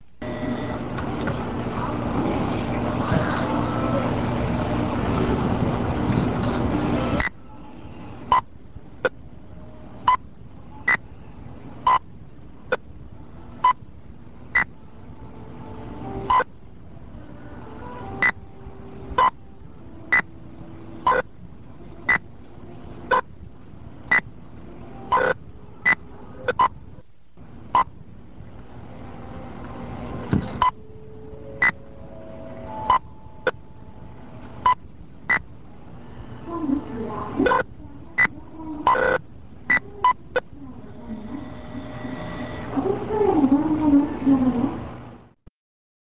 (c)??/46sec./97KB アタリ社が発売したブレイクアウトは日本に輸入され多くのコピー品が出回りましたがこれもそのひとつ。このソリッドな音がたまらない人もいるはず！でも、ツボはむしろ最後の「呼び出しアナウンス」？